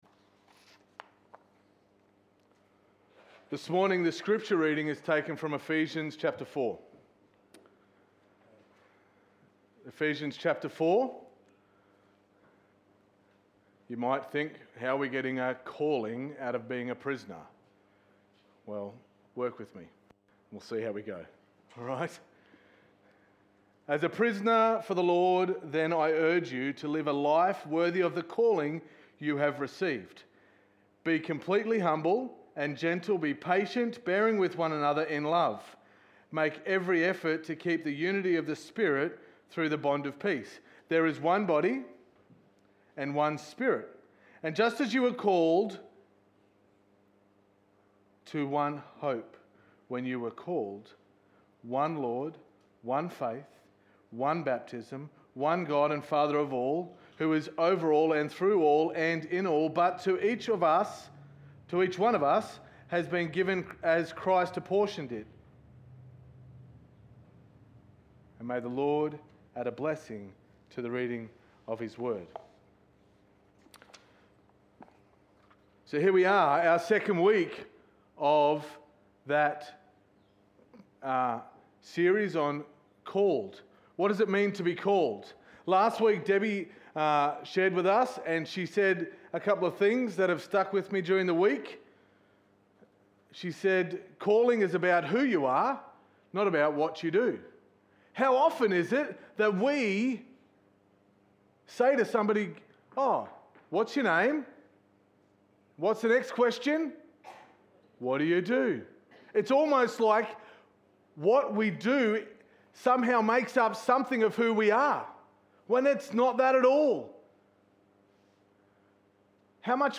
Sermon 08.11.2020